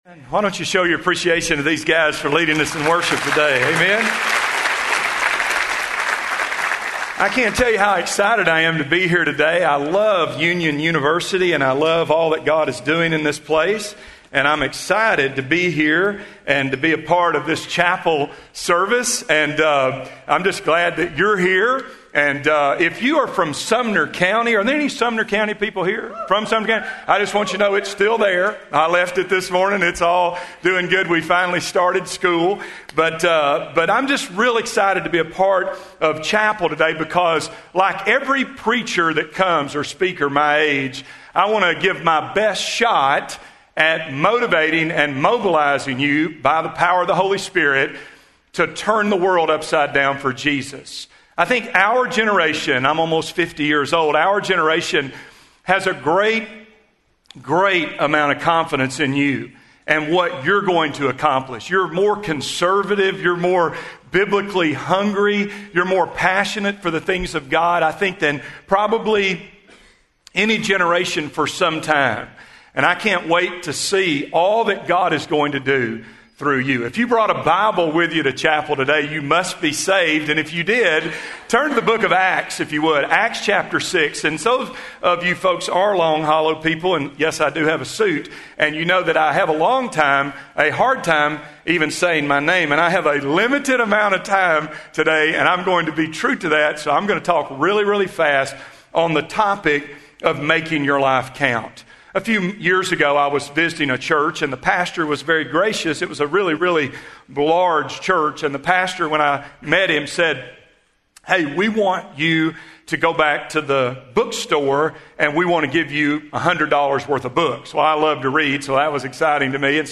The Union Audio Project | Union University, a Christian College in Tennessee